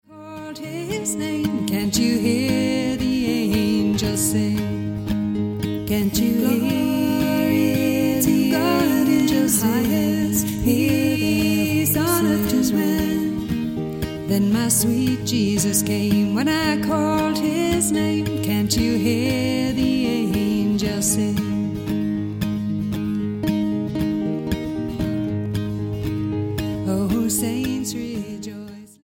STYLE: Roots/Acoustic
1960s blues folk vein